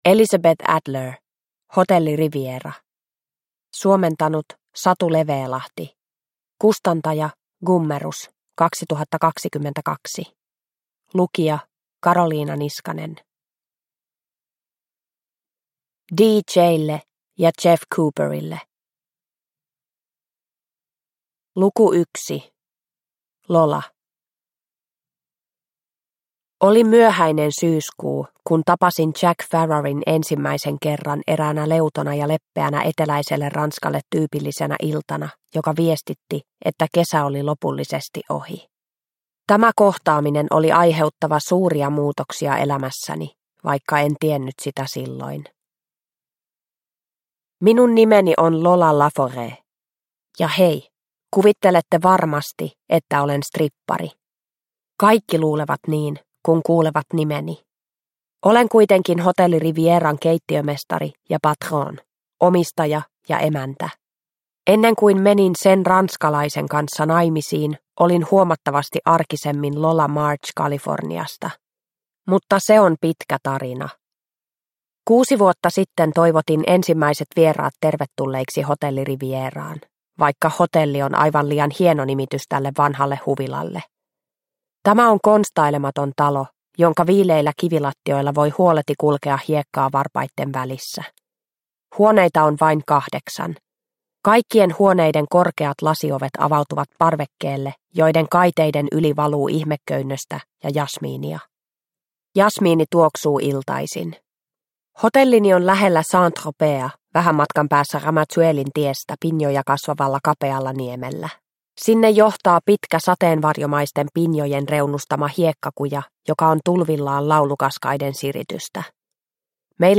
Hotelli Riviera – Ljudbok – Laddas ner